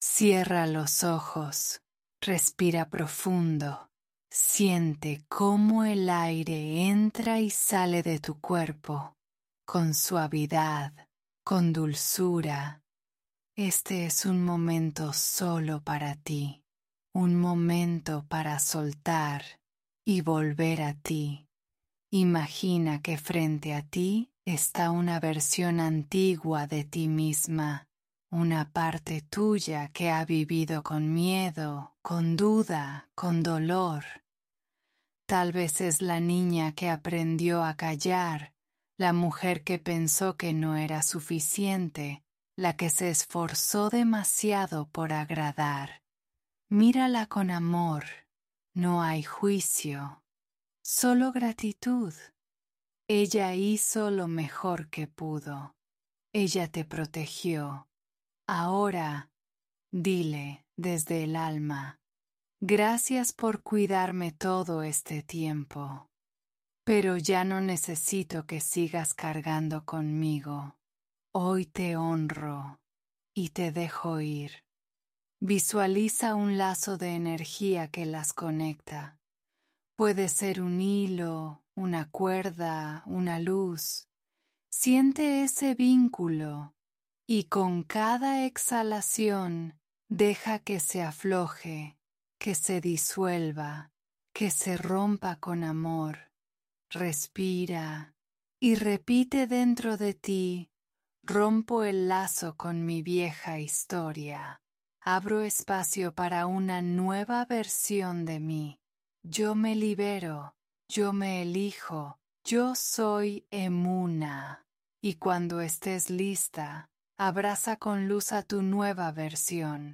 Meditación 🧘🏽‍♀